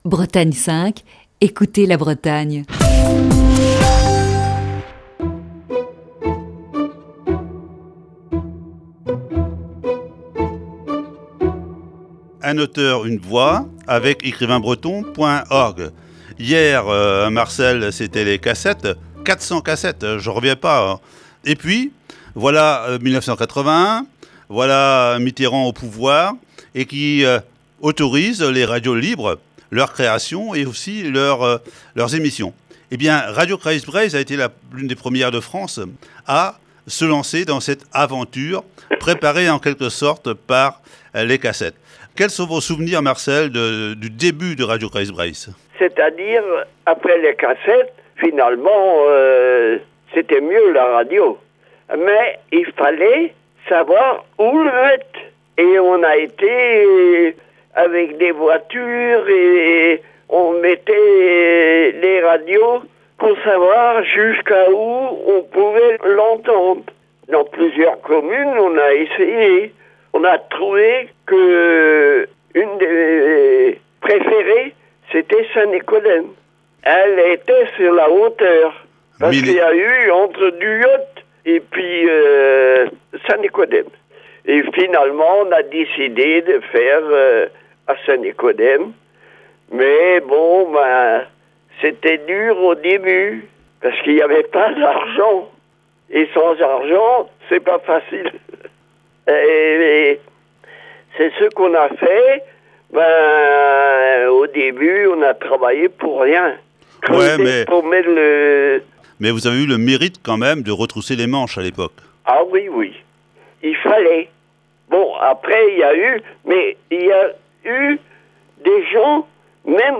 Émission du 4 juin 2021.